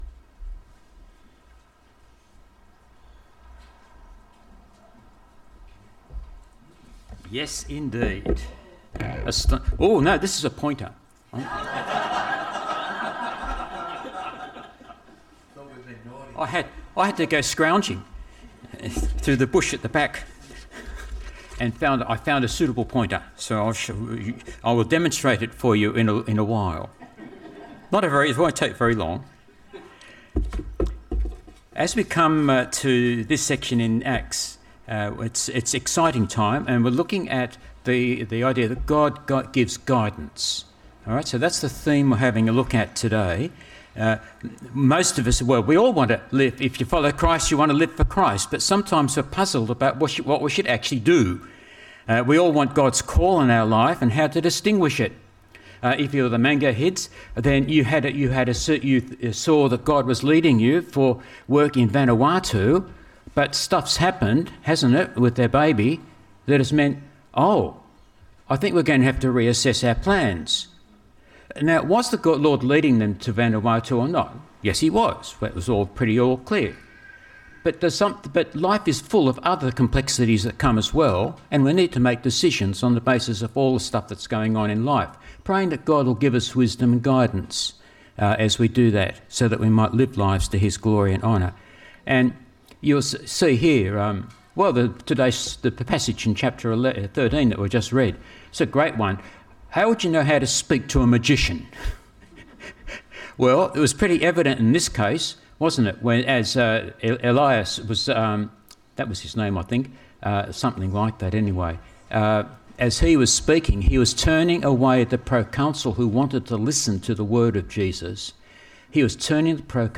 … continue reading 314 حلقات # Christianity # Religion # Anglican # Jesus # Helensburgh # Stanwell # Park # Helensburgh Stanwell Park Anglican A Church # Stanwell Park Anglican A Church # Sermons